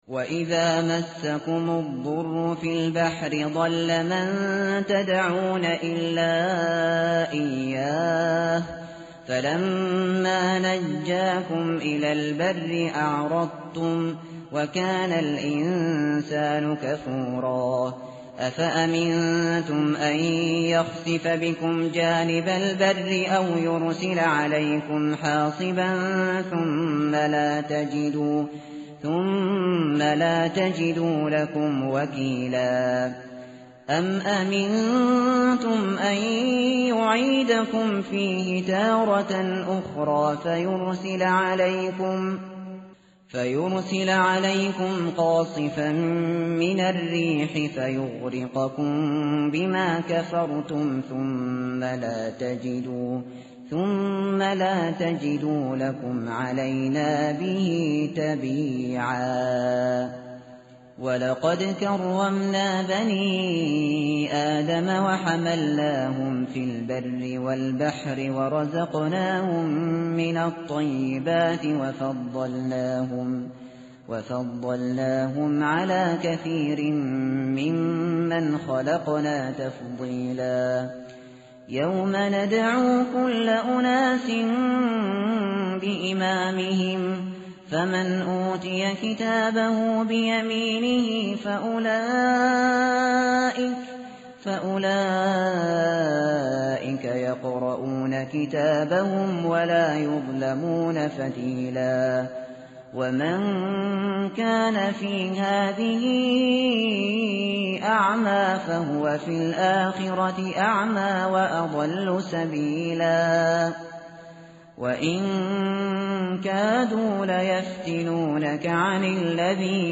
tartil_shateri_page_289.mp3